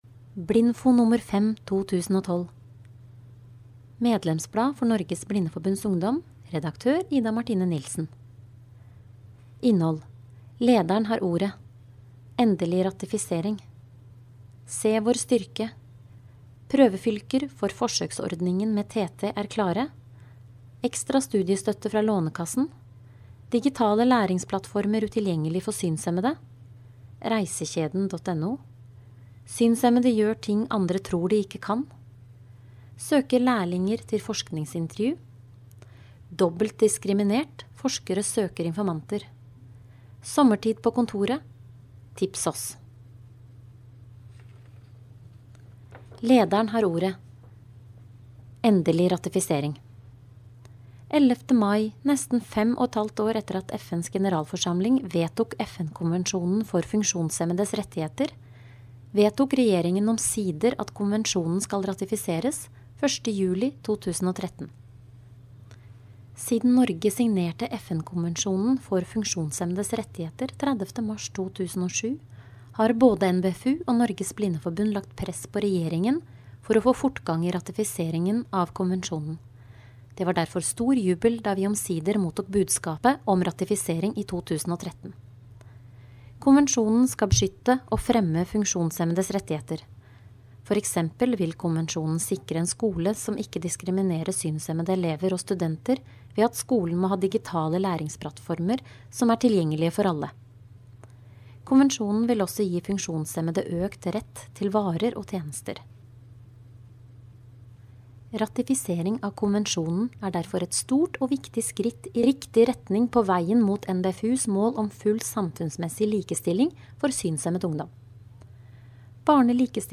Årets 5. utgave av medlemsbladet Blindfo er nå ute på nett! Denne utgaven kan du laste ned i rtf-format eller som innlest mp3-fil her.